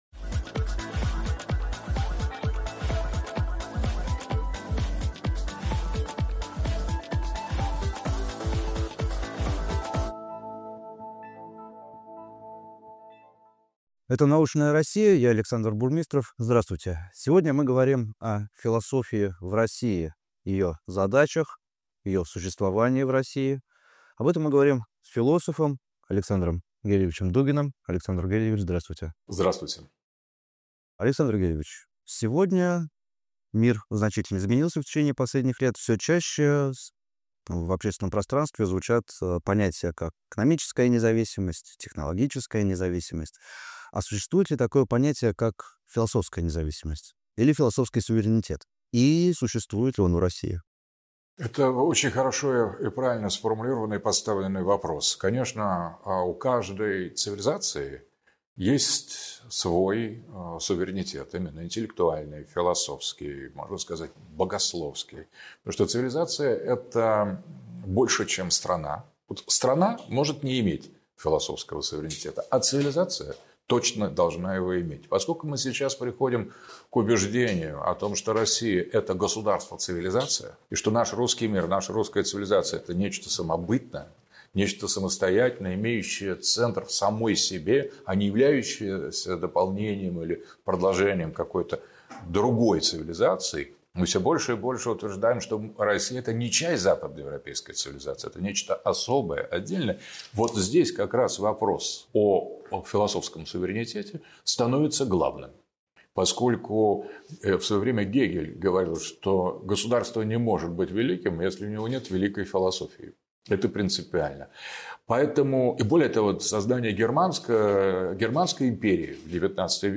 Философский суверенитет ― основа независимости государства. Интервью с Александром Дугиным
Почему подлинный философ ― риск для государства? Об этом ― в интервью с российским социологом, политологом и философом Александром Гельевичем Дугиным.